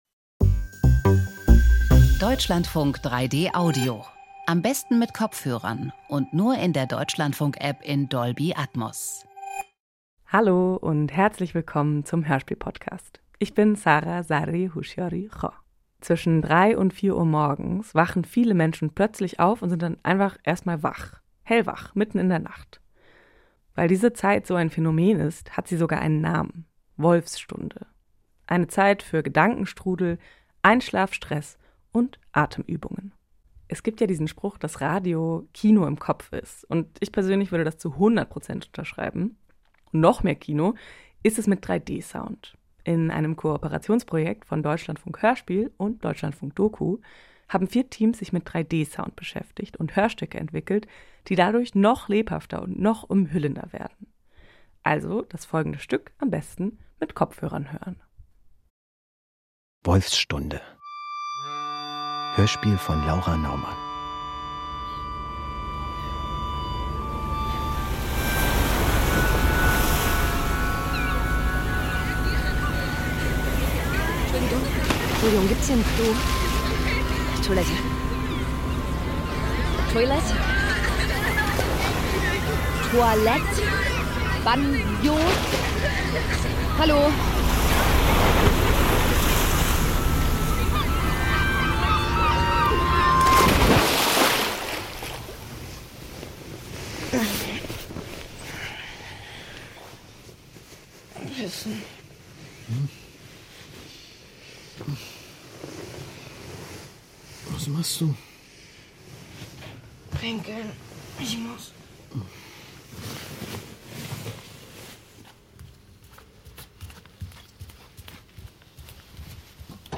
Hörspiele und Dokus in 3D (3/4) - Wolfsstunde
3D Audio ist wie geschaffen für Hörspiele und Dokus.